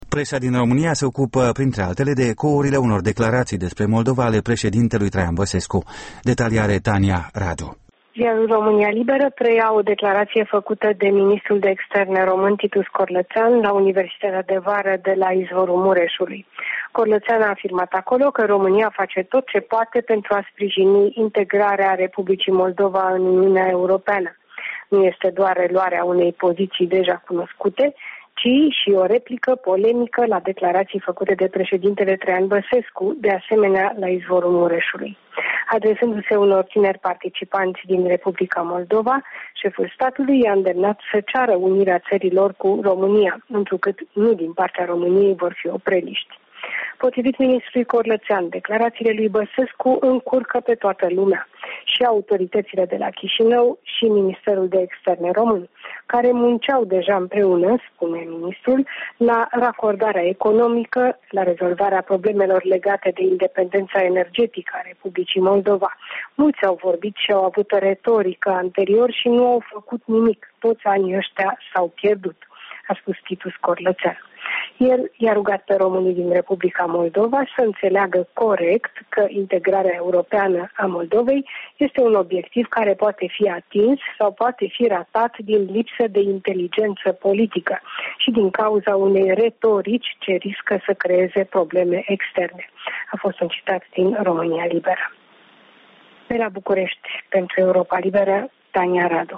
Revista presei bucureștene